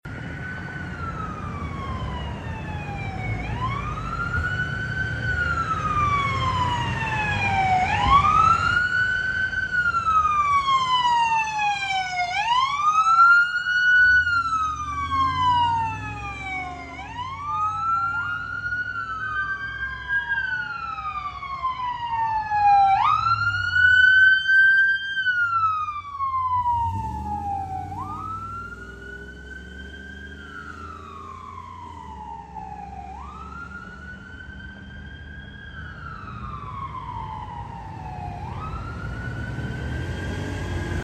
Rare response of Lutheran EMS sound effects free download
Rare response of Lutheran EMS admin car and Medic 33 eneoute to a med call in Peru